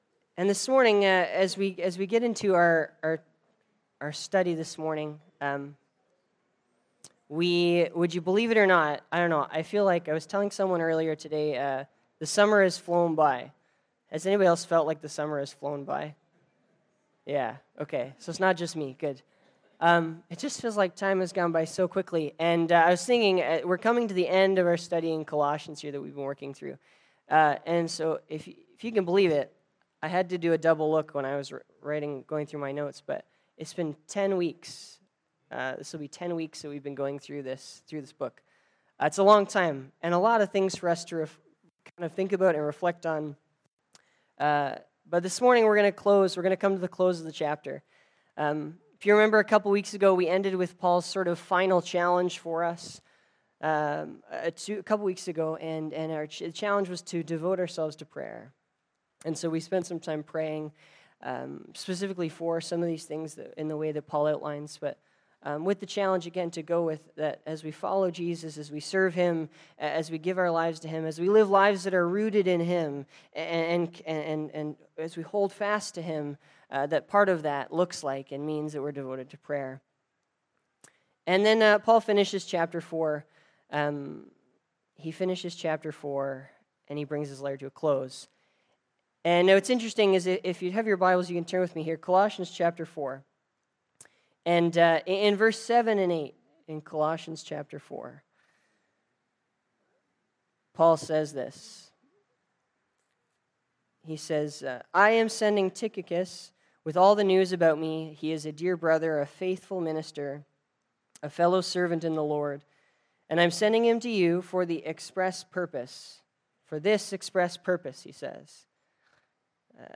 Sermons | Bethany Baptist Church